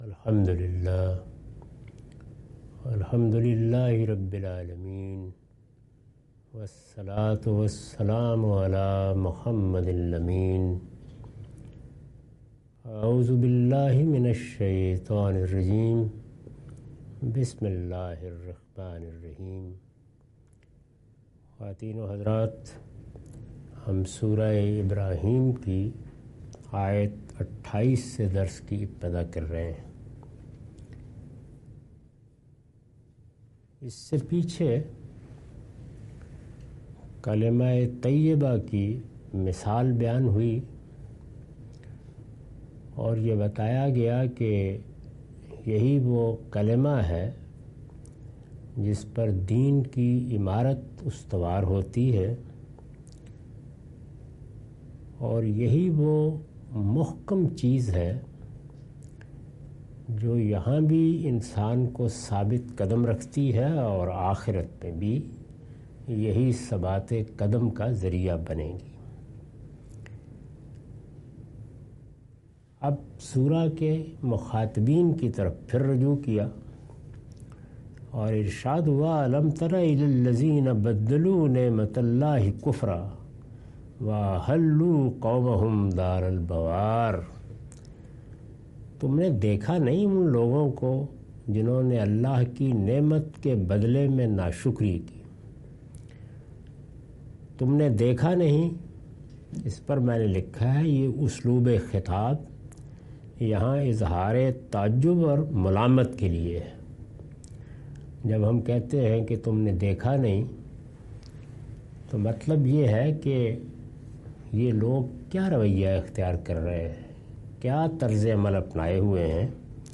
Surah Ibrahim- A lecture of Tafseer-ul-Quran – Al-Bayan by Javed Ahmad Ghamidi. Commentary and explanation of verses 28-34.